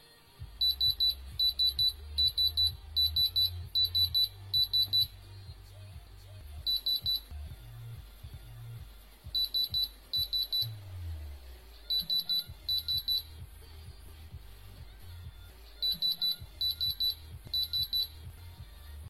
Tiếng Máy Test, check, kiểm tra… đồ thật giả
Thể loại: Tiếng đồ công nghệ
Description: Tiếng Máy Test, check, kiểm tra... đồ thật giả, âm thanh bíp bíp của tiếng Máy kiểm tra kim cương trang sức có độ chính xác cao, Bút kiểm tra kim cương chuyên nghiệp...
tieng-may-test-check-kiem-tra-do-that-gia-www_tiengdong_com.mp3